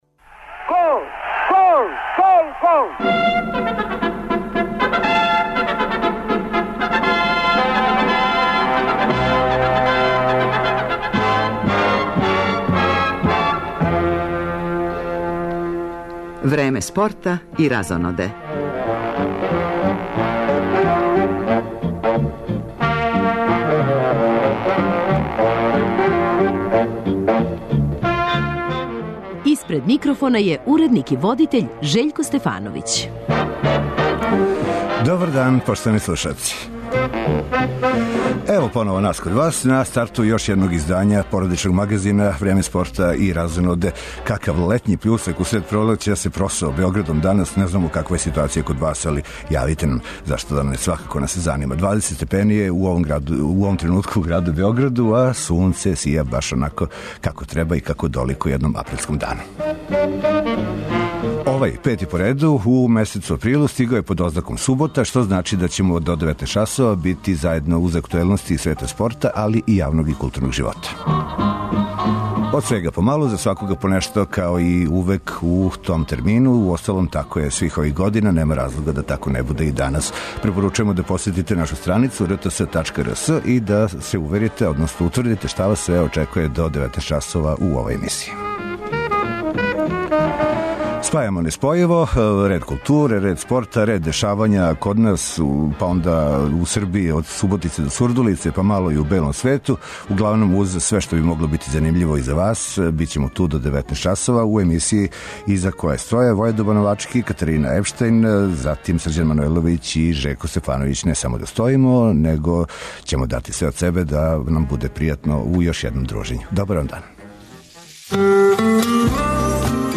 Породични магазин Радио Београда 1 и овог викенда обраћа се свим генерацијама. Из спорта - пратимо кретање резултата на утакмицама 22. кола Супер лиге Србије.